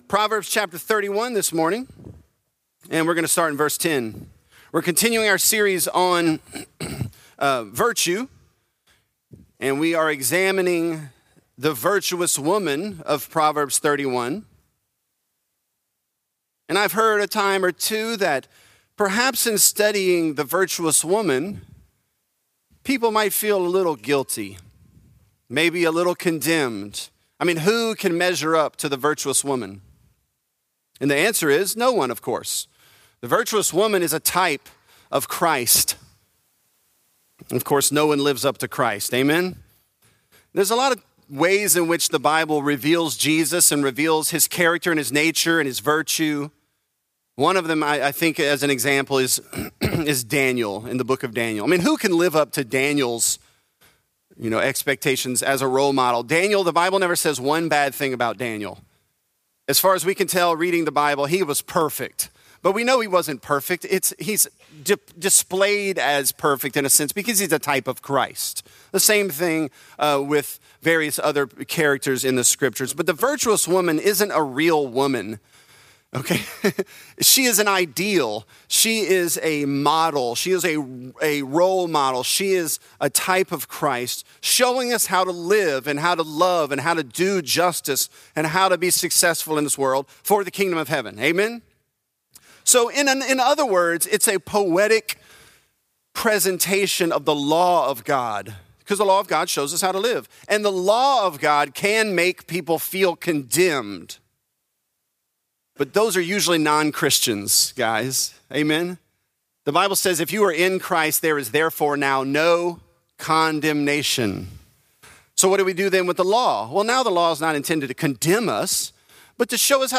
Virtuous: She Considers A Field | Lafayette - Sermon (Proverbs 31)